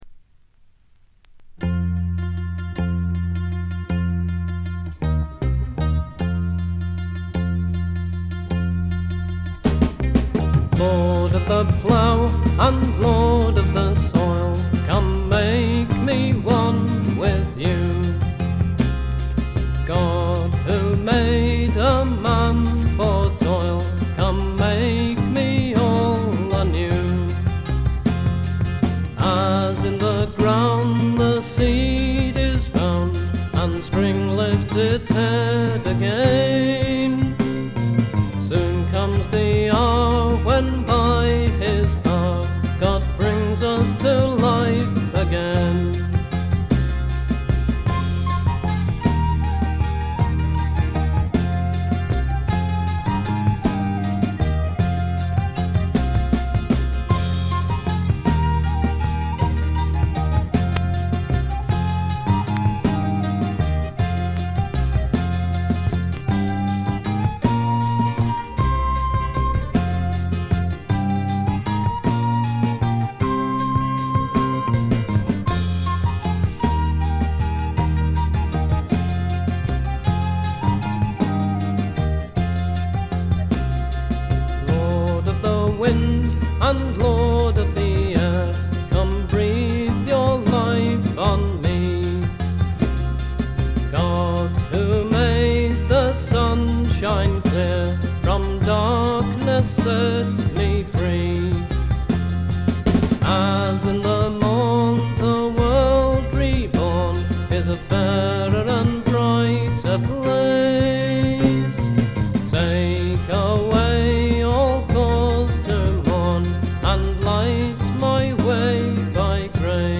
見た目に反してプレスがいまいちのようで、ところどころでチリプチ。プツ音少々。
リヴァプールの男性4人組フォークバンドによるメジャー級の素晴らしい完成度を誇る傑作です。
格調高いフォークロックの名盤。
試聴曲は現品からの取り込み音源です。
Recorded At - Canon Sound Studio, Chester